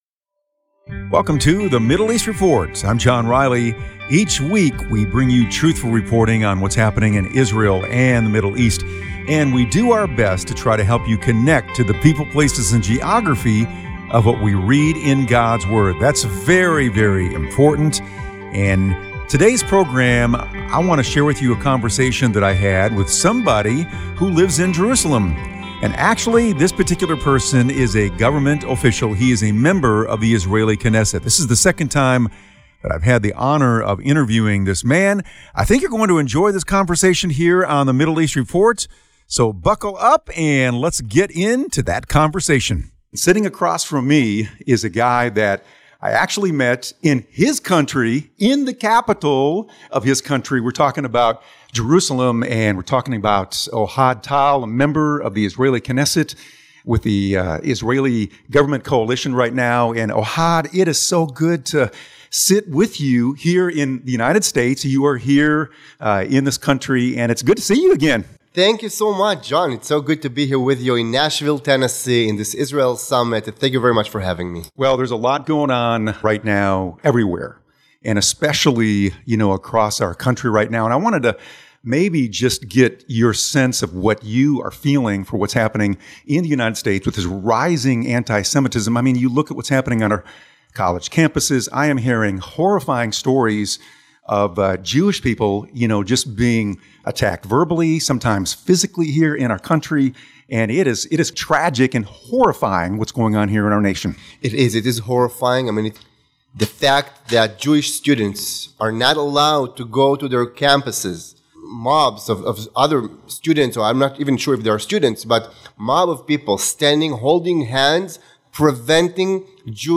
I spoke to Ohad Tal, a member of the Israeli Knesset and member of the current government coalition about a range of current issues facing Israel.